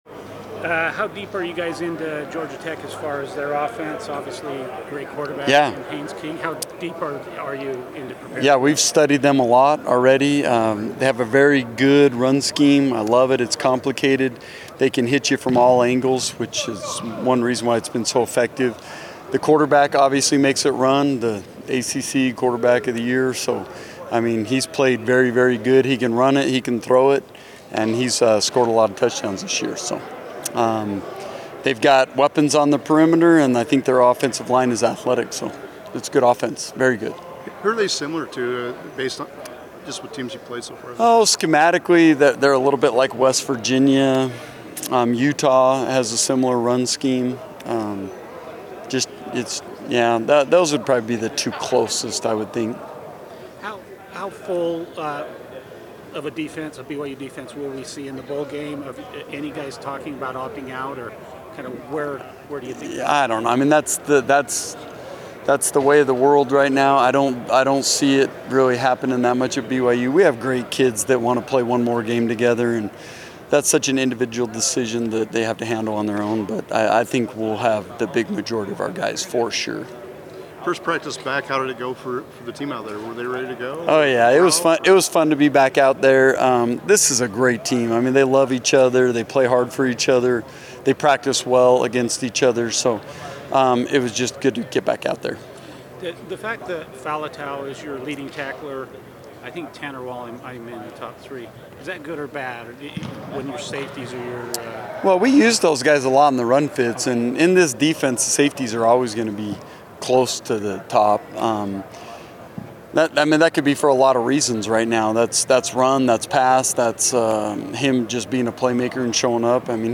Hear from BYU athletes as they get ready for their big games.
Football Media Availability